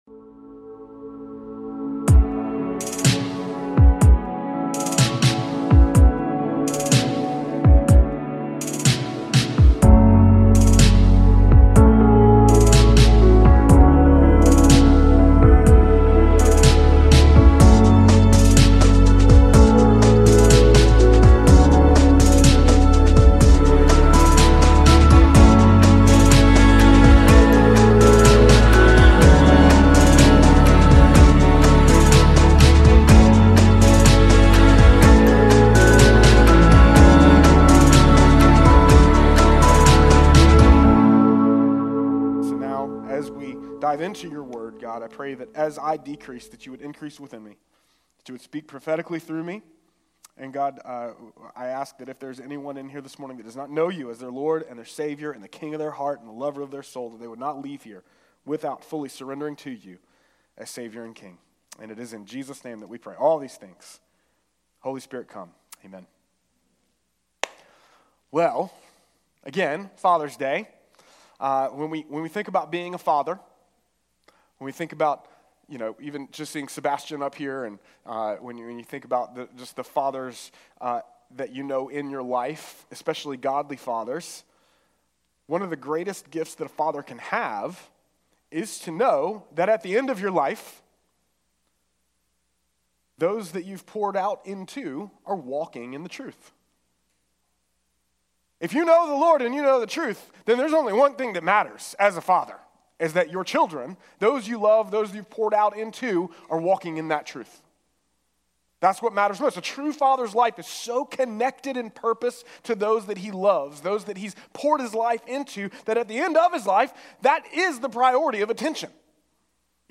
A message from the series "Poured Out."